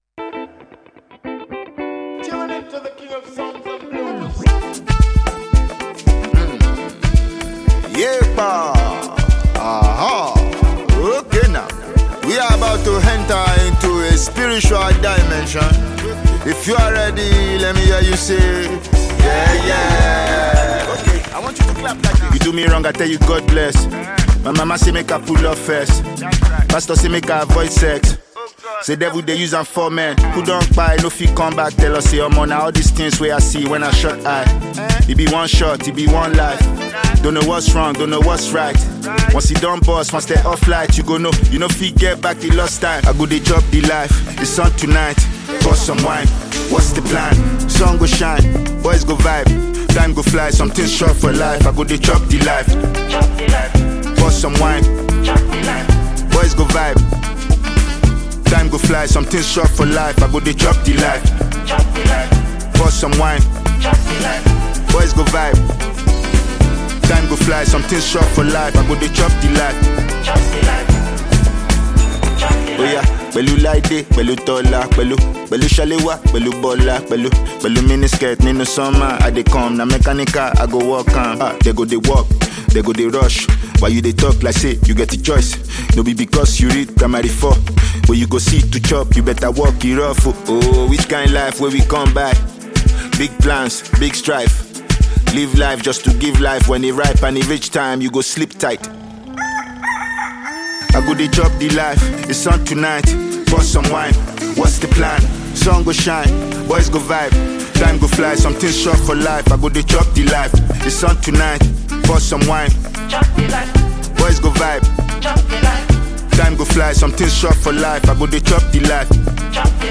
Genre: Afrobeats
Heavily talented Nigerian rapper and actor